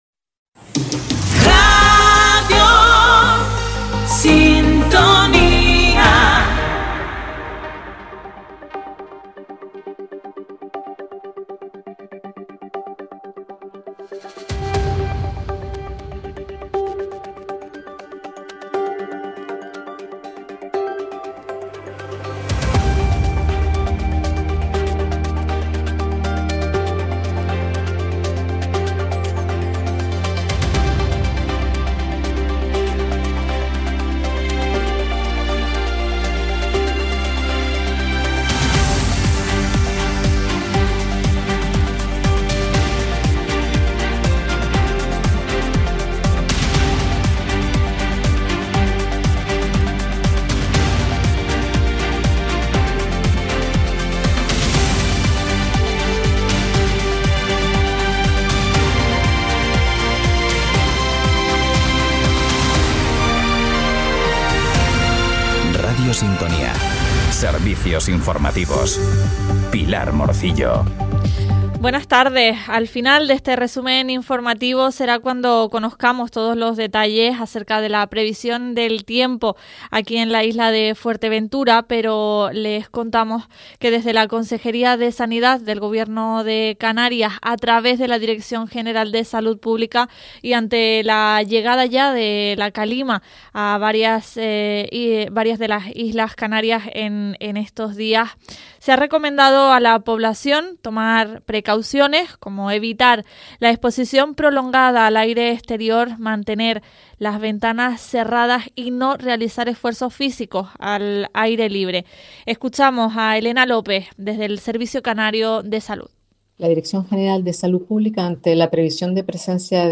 En Radio Sintonía Fuerteventura les mantenemos al día de la actualidad local y regional en nuestros informativos diarios a las 13.15 horas. Por espacio de 15 minutos acercamos a la audiencia lo más destacado de los distintos municipios de la isla, sin perder la atención en las noticias regionales de interés general.